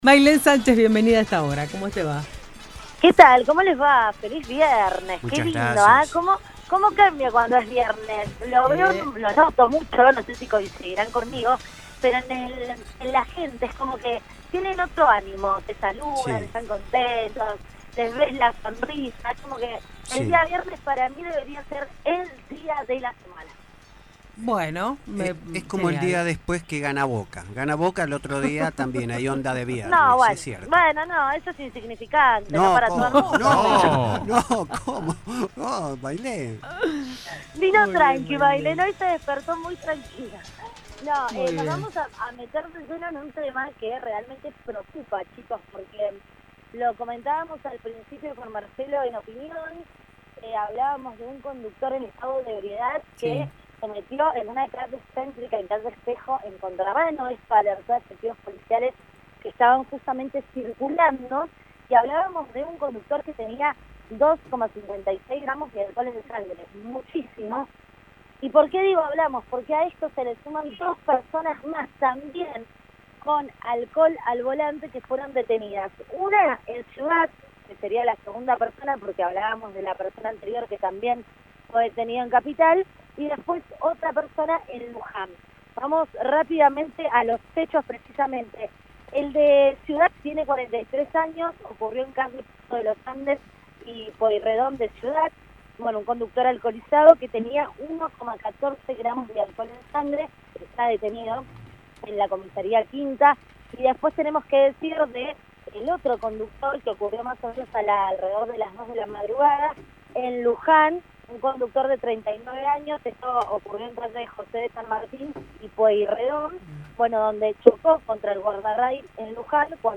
LVDiez - Radio de Cuyo - Móvil de LVDiez - Preocupación por conductores manejando en estado de ebriedad